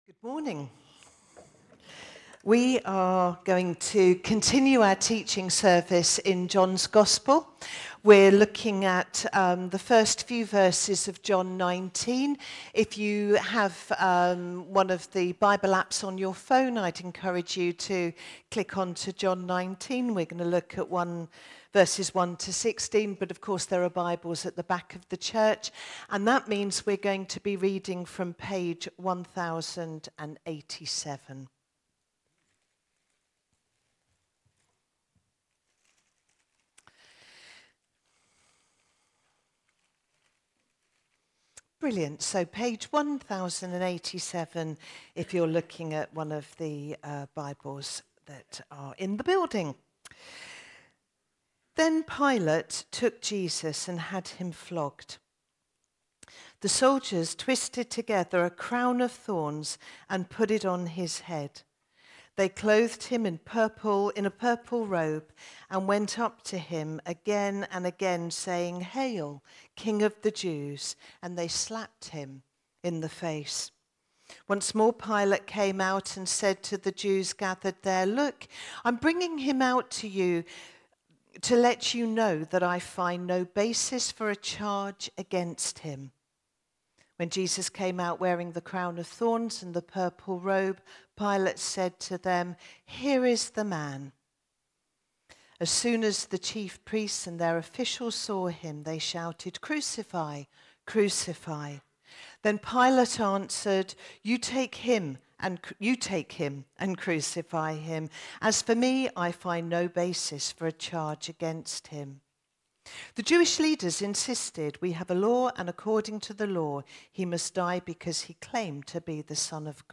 God-in-Person-Pilate-swayed-by-the-crowd-Sermon.mp3